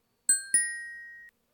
Hovering_buzzard.ogg